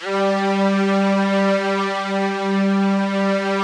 SPCSTR. G3-L.wav